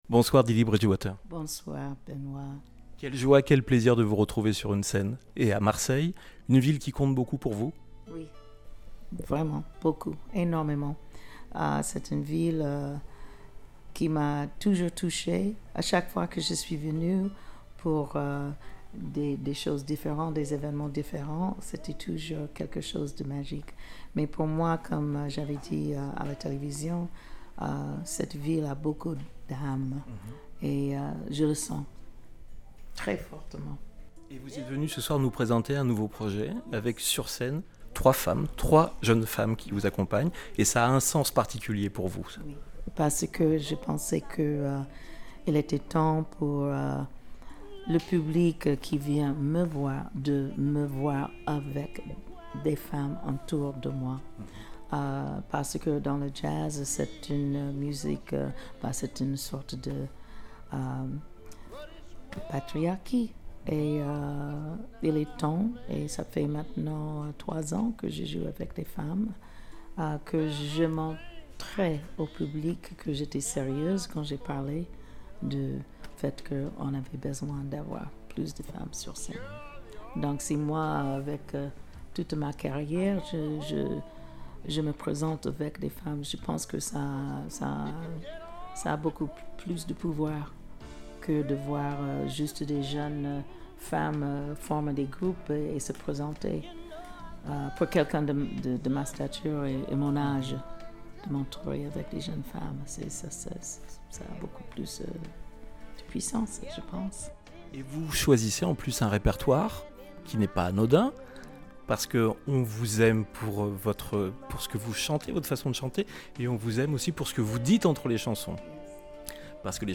jazz festival dee-dee-bridgewater festivals interview festival-de-jazz